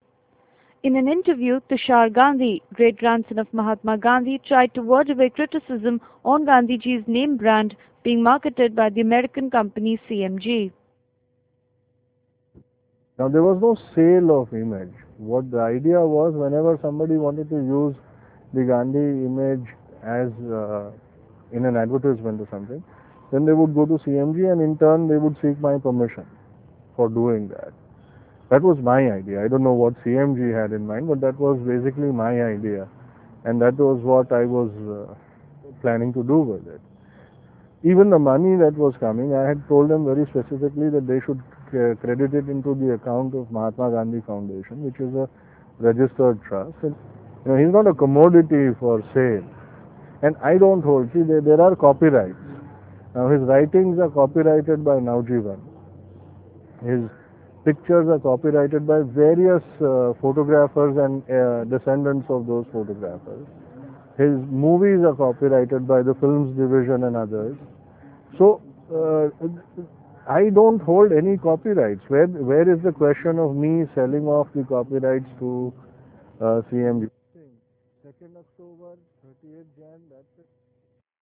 In an interview, Tushar Gandhi, great grandson of Mahatma Gandhi tries to ward off criticism on Gandhiji's name 'brand' being marketed by the American company, CMG.